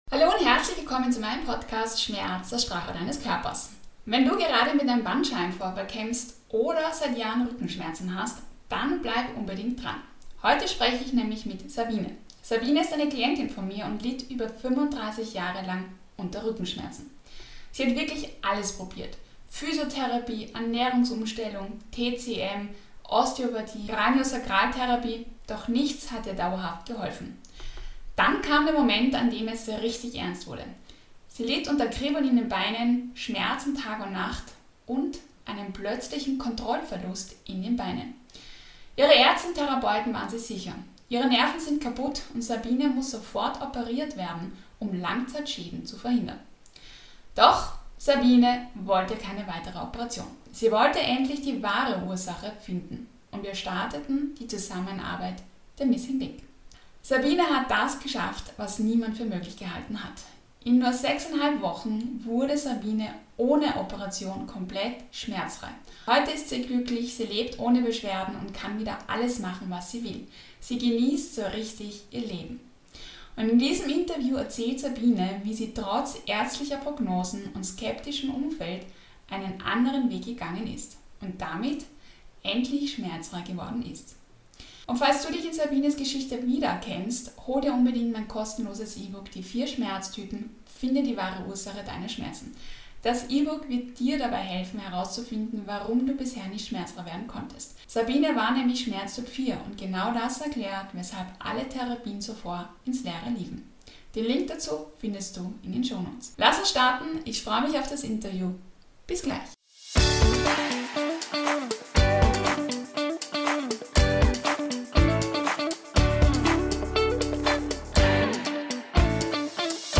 In diesem Interview erzählt sie, wie sie es geschafft hat, in nur 6,5 Wochen komplett schmerzfrei zu werden.